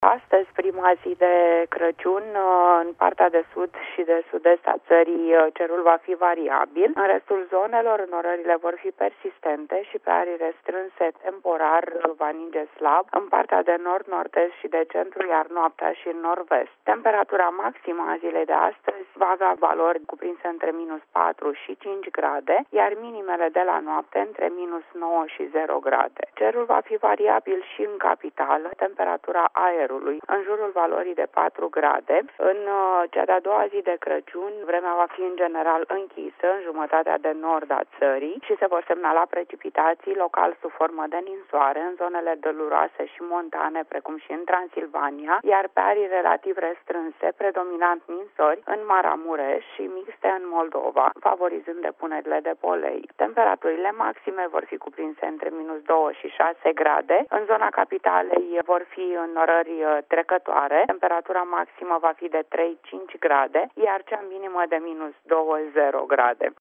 stiri-25-dec-meteo-craciun.mp3